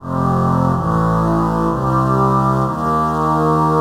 Sandy Riff_126_F.wav